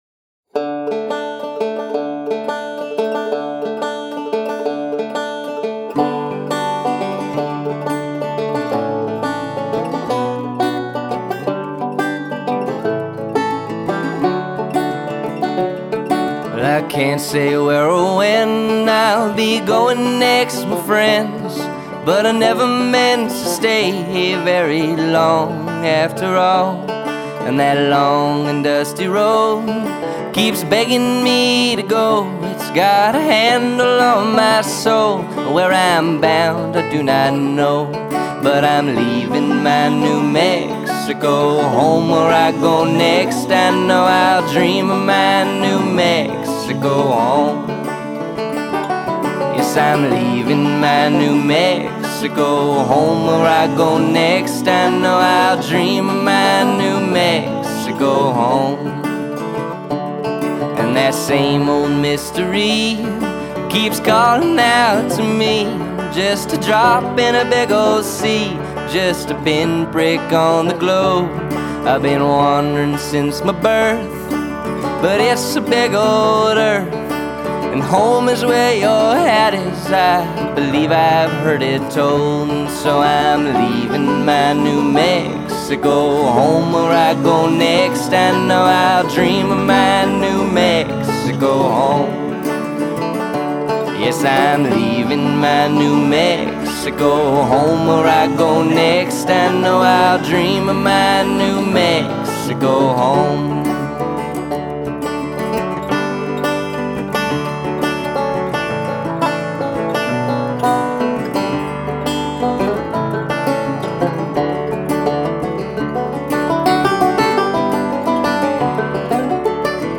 guitar, vocals, piano
banjo
backup vocals